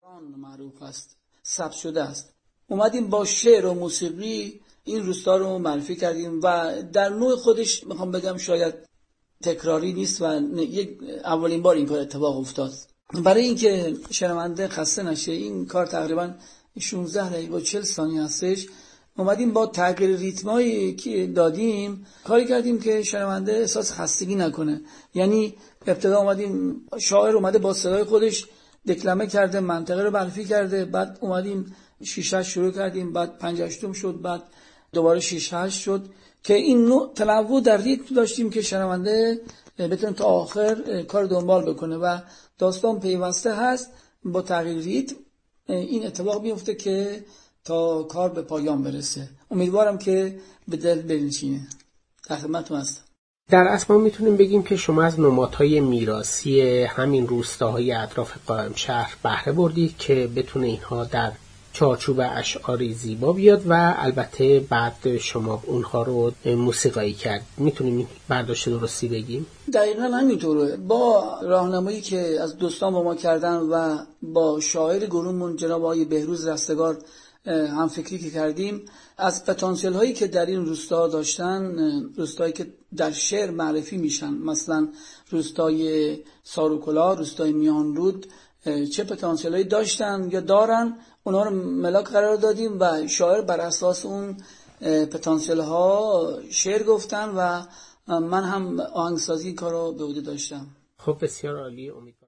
به گزارش خبرنگار ایکنا؛ در برنامه این هفته مجله موسیقی «ایل بانگ» که در ساعات پایانی جمعه‌ هفتم مرداد از رادیو فرهنگ پخش شد پیرامون موسیقی مازندران گفت‌وگو شد.